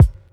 Kicks
WU_BD_255.wav